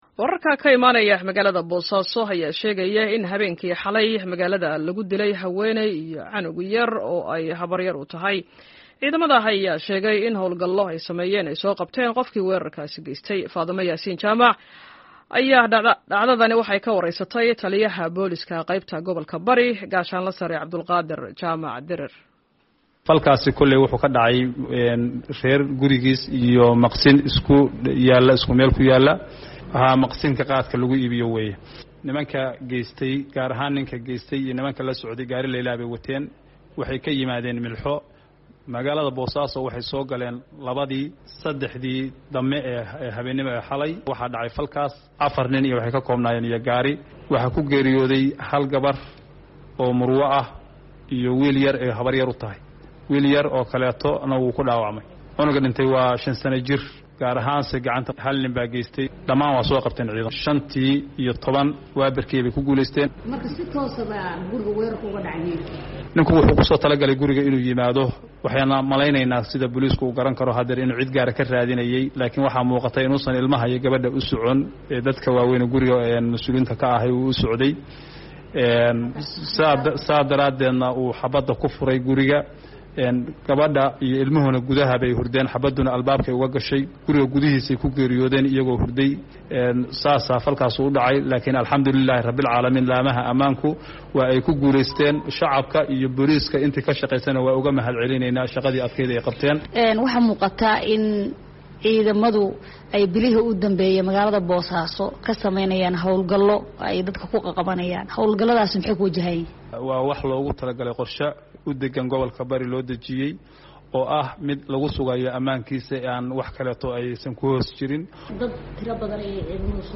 Booliiska Boosaaso oo ka hadlay dil ka dhacay magaalada